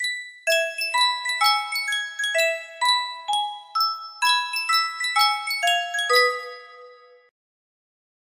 Sankyo Miniature Music Box - IWAHHFC GL music box melody
Full range 60